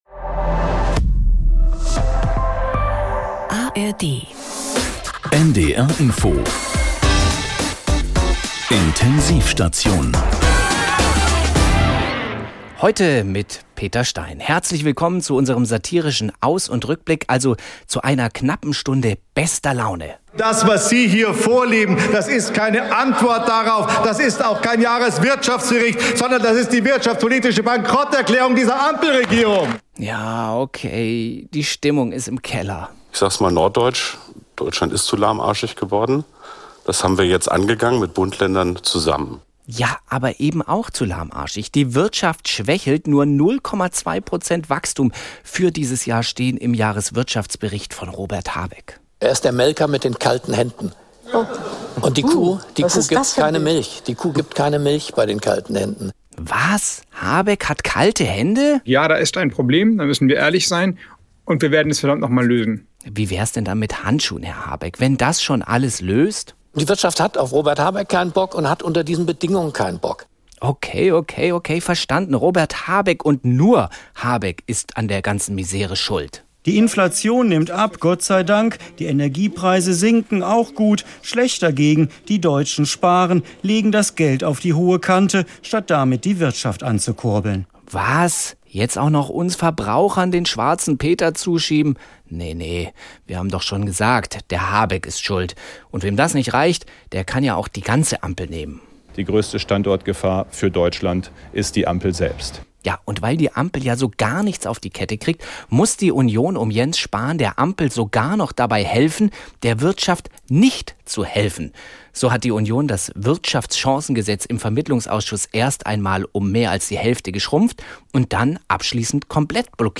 In dieser Ausgabe der Intensiv-Station sind folgende Musiktitel zu hören: